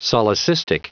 Prononciation du mot solecistic en anglais (fichier audio)